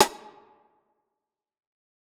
Destroy - Perc Thug.wav